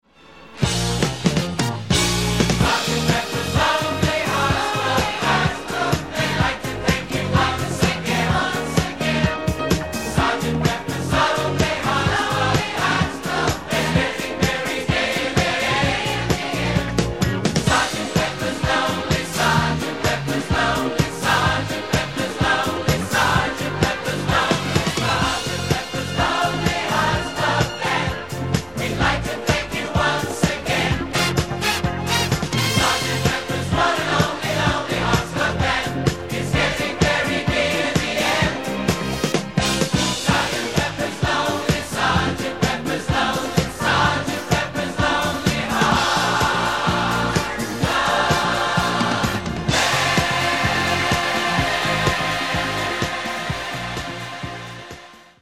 RECORDED AND MIXED AT CHEROKEE STUDIOS, LOS ANGELES